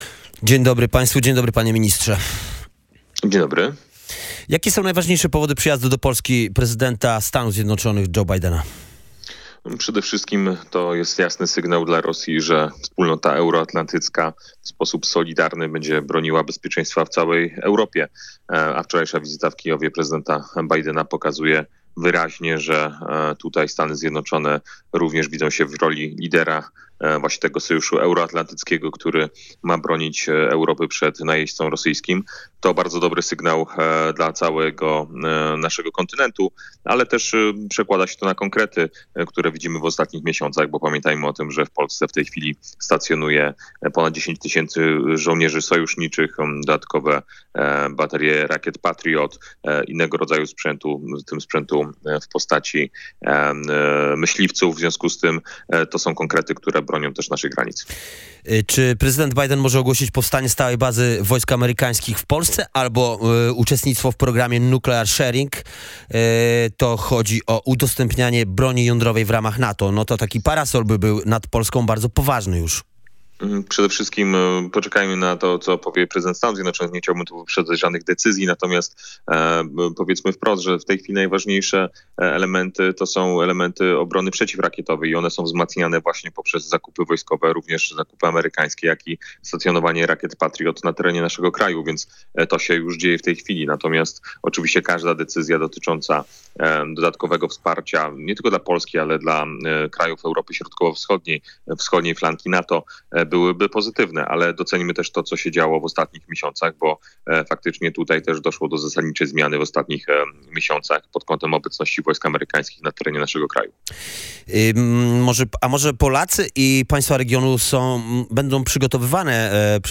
Osoby młode, które dzisiaj wspierają Rafała Trzaskowskiego, nie zdają sobie sprawy, o jakim tempie zmian mówimy – mówił w Radiu Gdańsk Piotr Müller, rzecznik prasowy rządu, poseł Prawa i Sprawiedliwości.